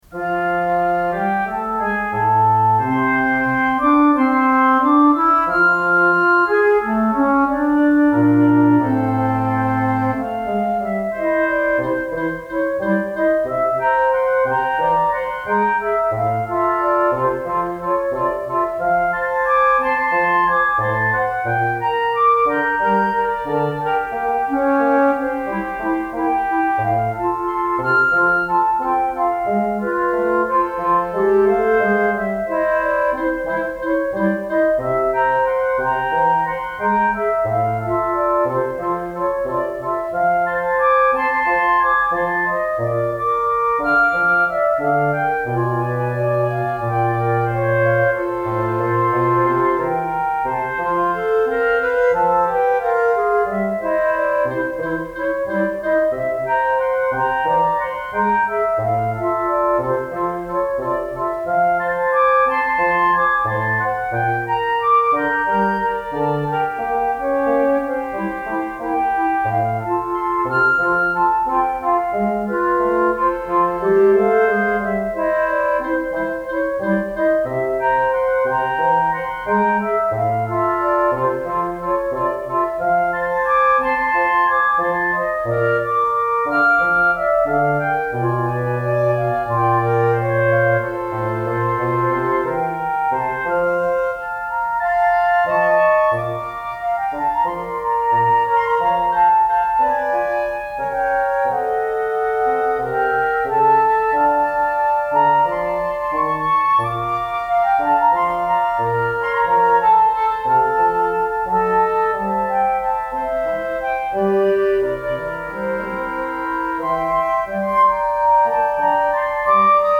Wind Quartet
Flute, Oboe Clarinet, Bassoon
A slow and poignant serenade with a gentle tango rhythm,
Works very well as a wind ensemble and is an excellent